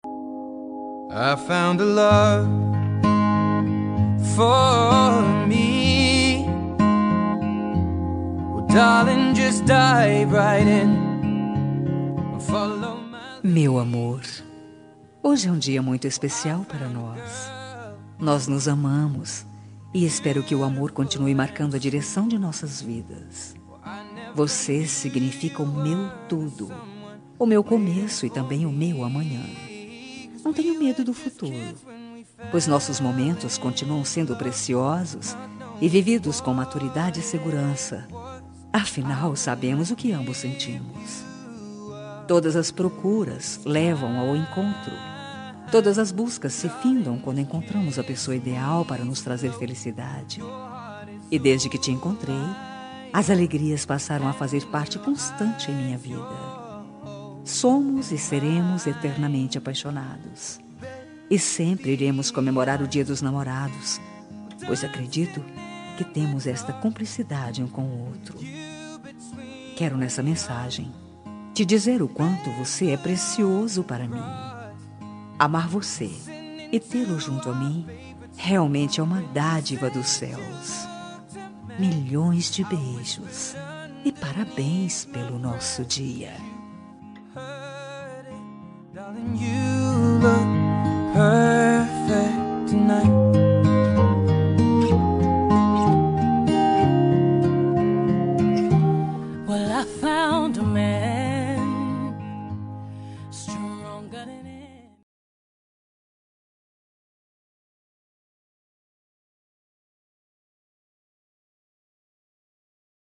Dia dos Namorados – Para Marido – Voz Feminina – Cód: 6884